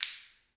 Index of /90_sSampleCDs/Roland - Rhythm Section/PRC_Clap & Snap/PRC_Snaps